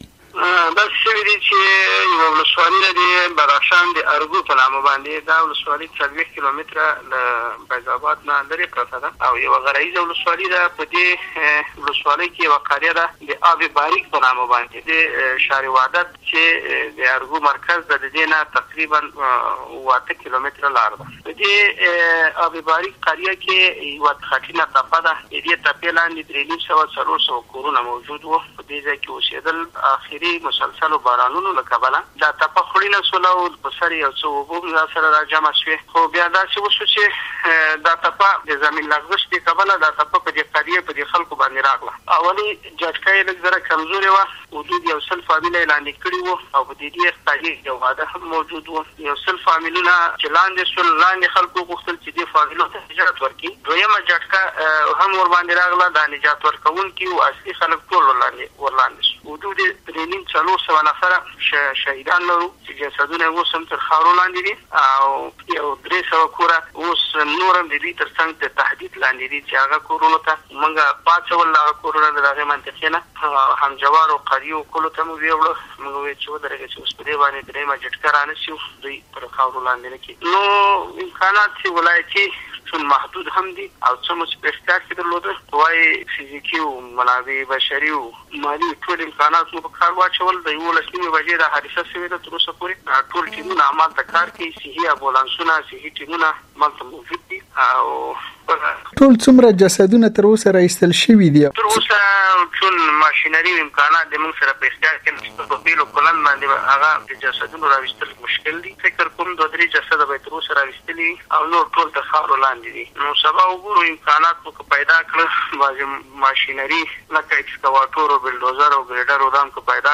د بدخشان د مرستیال والي سره د امریکا غږ مرکه